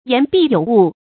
言必有物 注音： ㄧㄢˊ ㄅㄧˋ ㄧㄡˇ ㄨˋ 讀音讀法： 意思解釋： 指言論或文章內容充實。